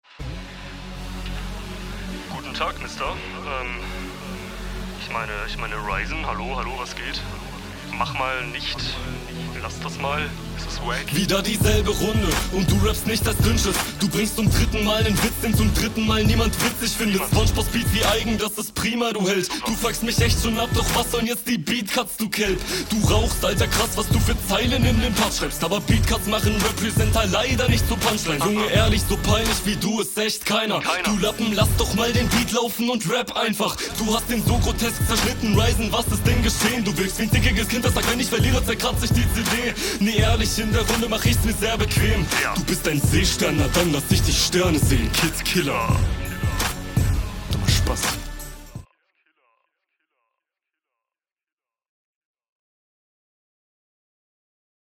Doubletime nice.